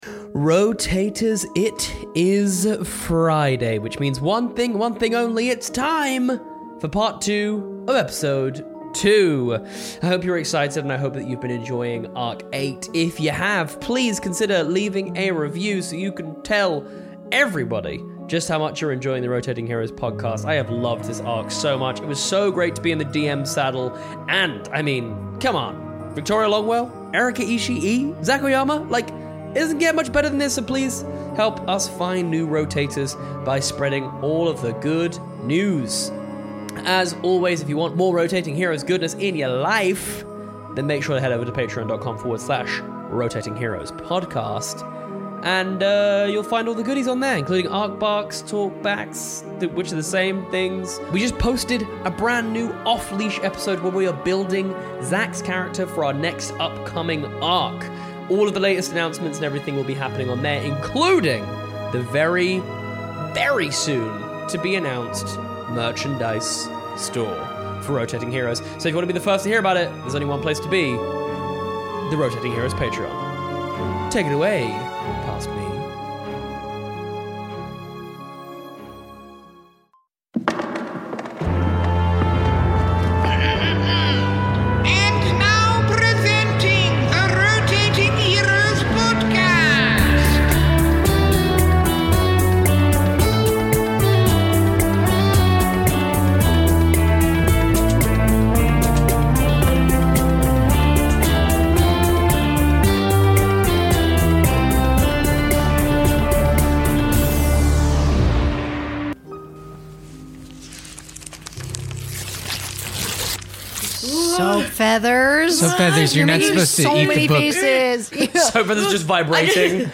Zac Oyama is playing Jin Erika Ishii is Soapfeathers